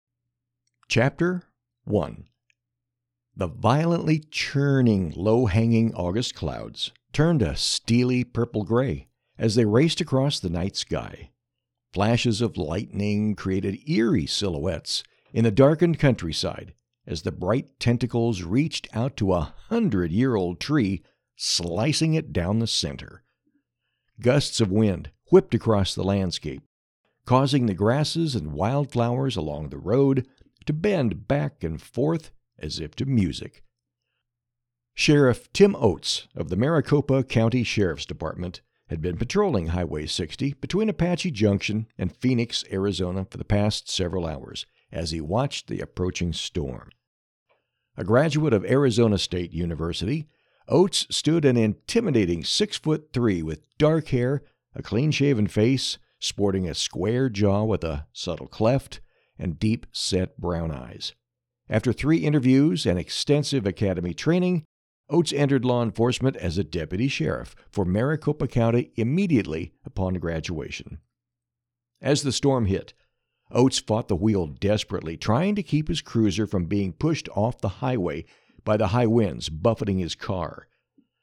Audio Book - Ghost of the Lost Dutchman's Mine short clip (mp3)
Audio Book - Ghost of the Lost Dutchman_s Mine.mp3